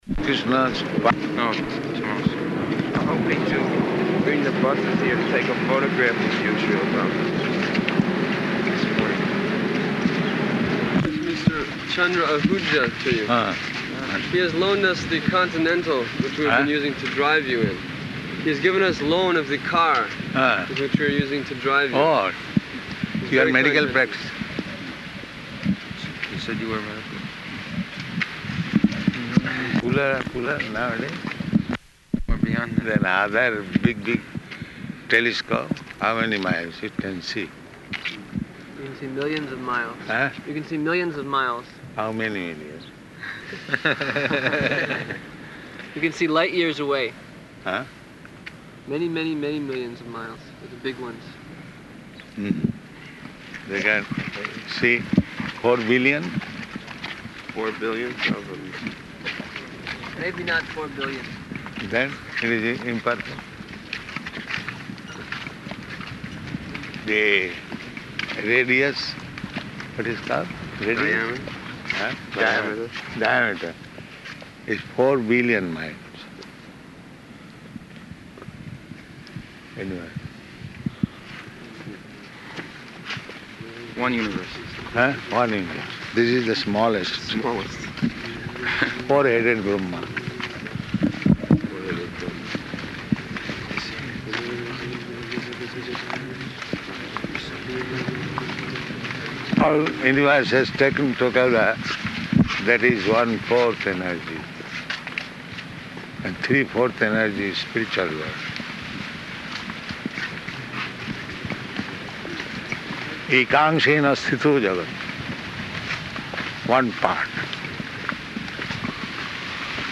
-- Type: Walk Dated: July 11th 1975 Location: Chicago Audio file